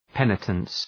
{‘penətəns}